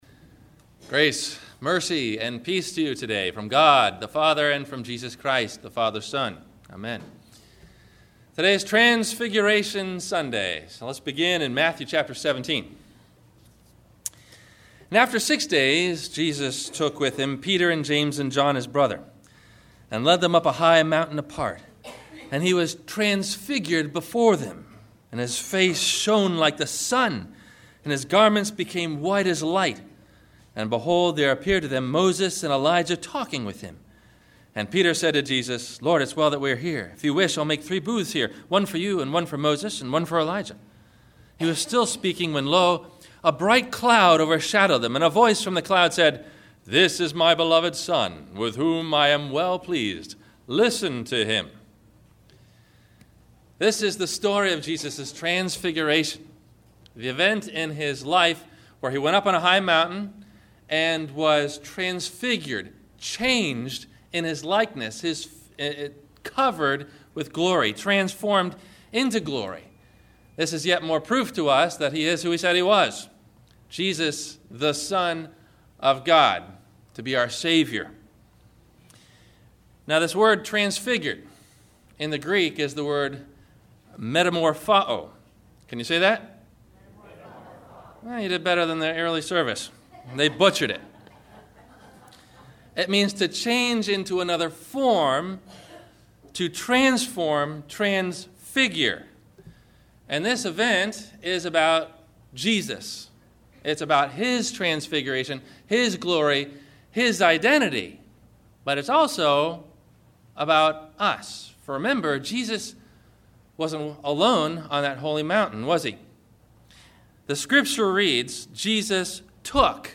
How Are We Changed ? – Sermon – March 06 2011